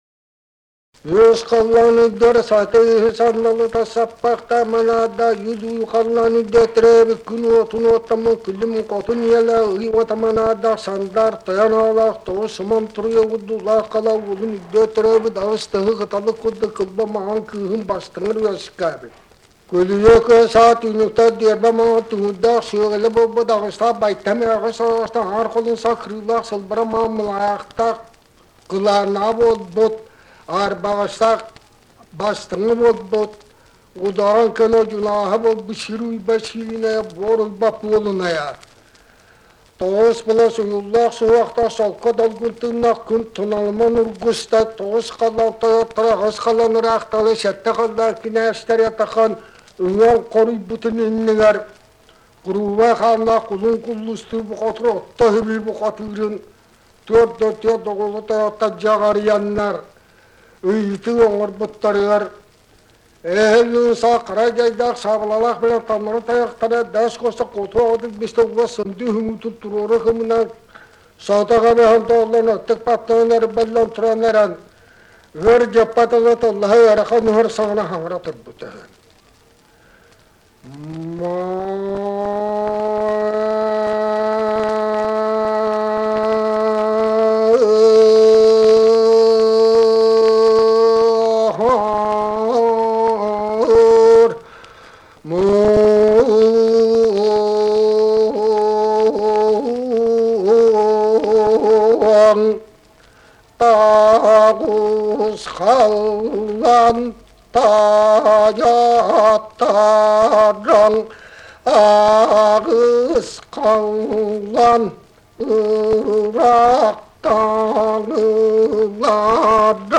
Якутский героический эпос "Кыыс Дэбилийэ"
Вступительный речитатив и песня Кулун Куллустуура из олонхо.